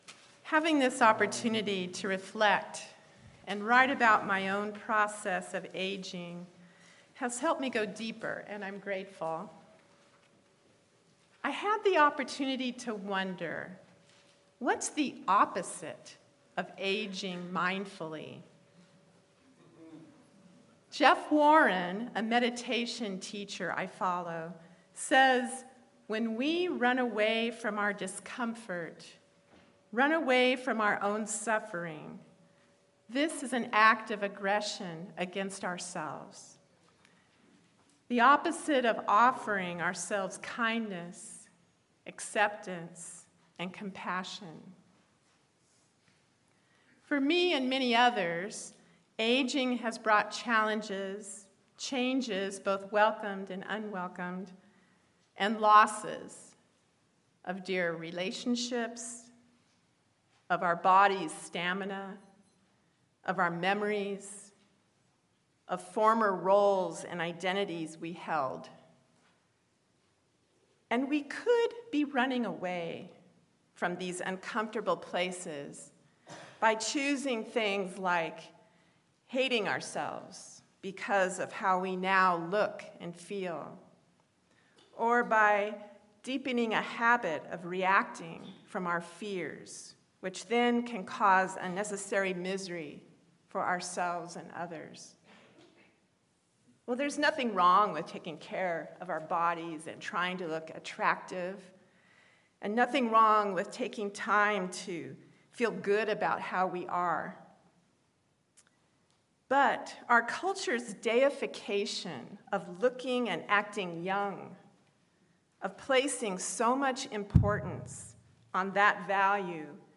Sermon-Spirituality-of-Aging.mp3